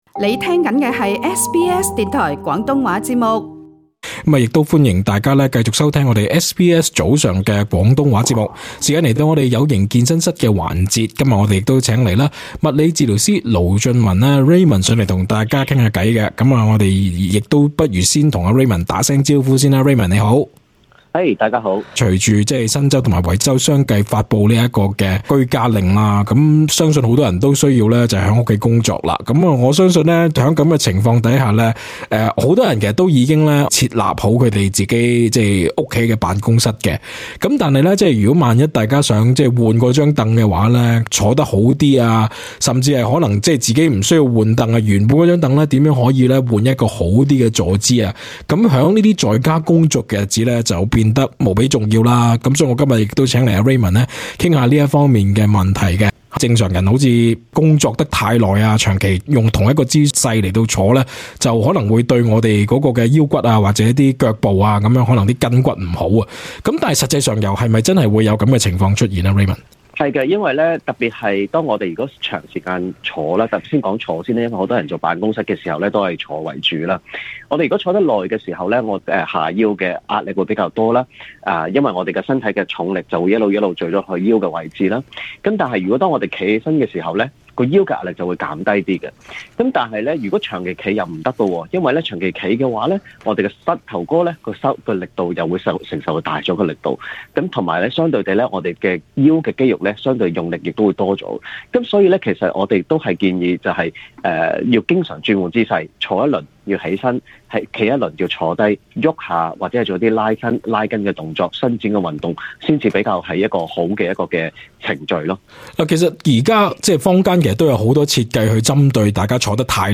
想知更多訪問內容，請聽足本錄音。